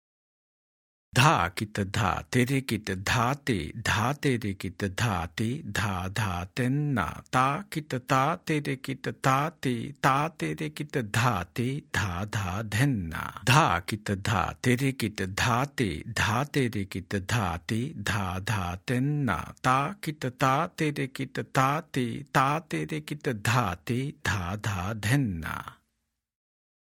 Spoken – Version 2